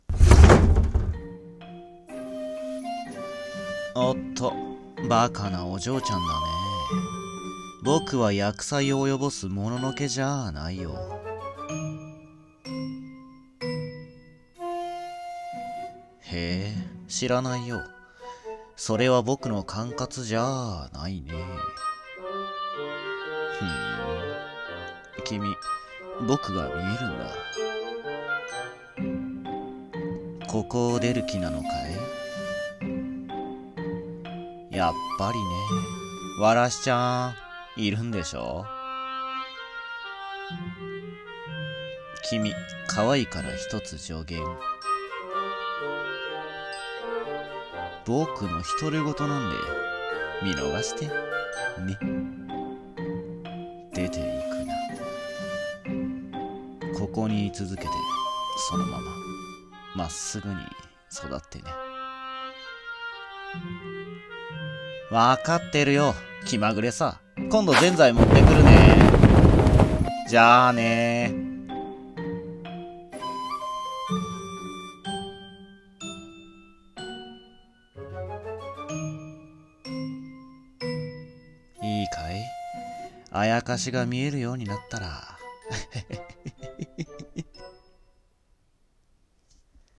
アヤカシの気紛れ 【妖怪 和風 三人声劇】